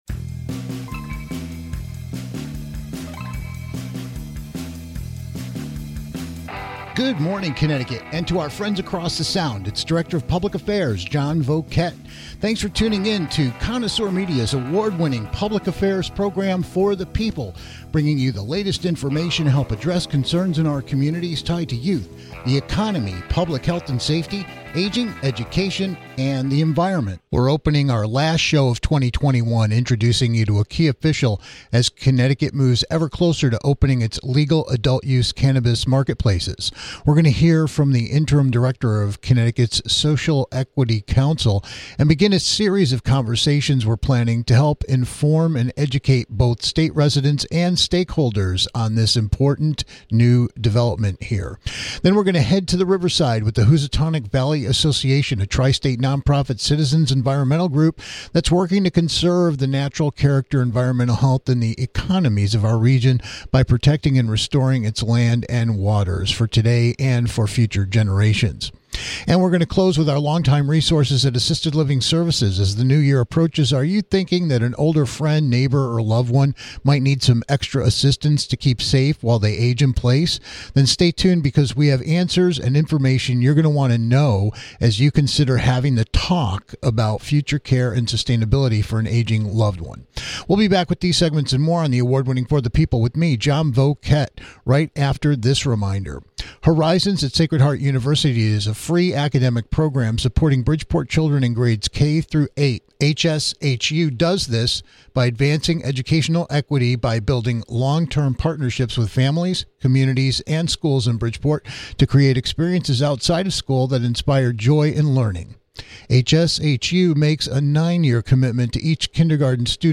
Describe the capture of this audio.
Meet a key official as Connecticut moves ever closer to opening its legal adult cannabis marketplaces as we ring up the interim director of Connecticut's Social Equity Council to begin a series of conversations to help inform and educate both state residents and stakeholders.